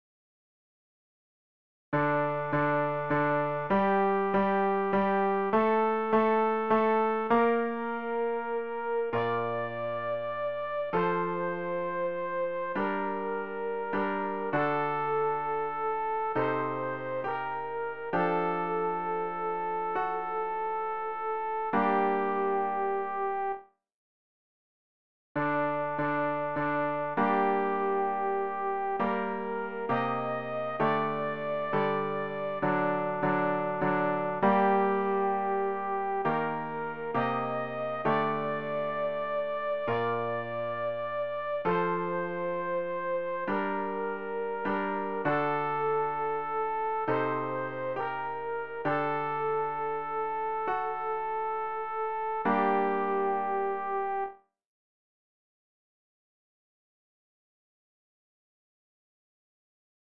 rg-862-lass-uns-den-Weg-sopran.mp3